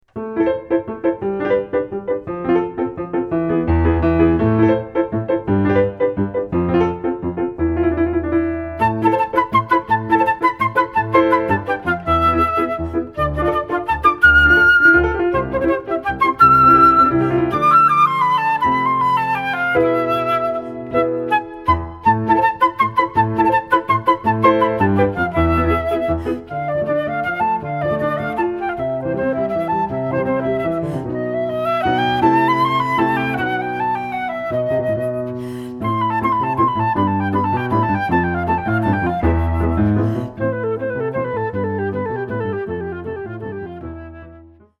Pour flûte et piano